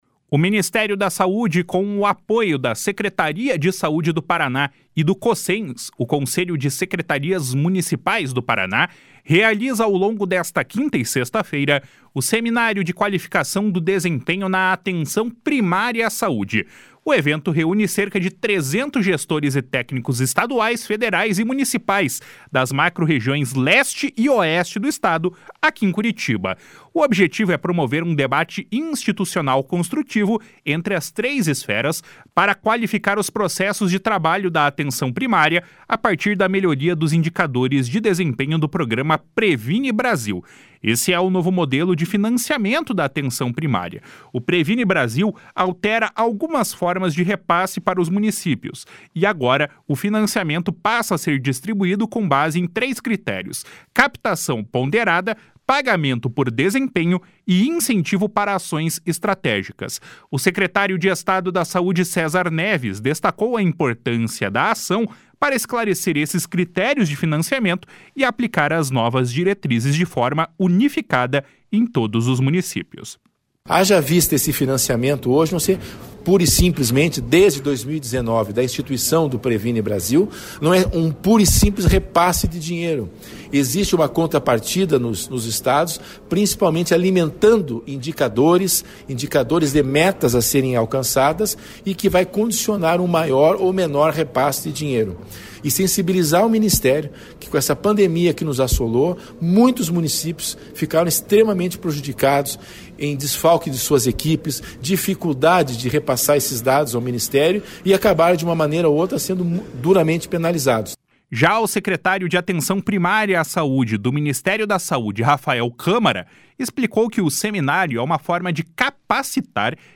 // SONORA CÉSAR NEVES //
// SONORA RAPHAEL CÂMARA //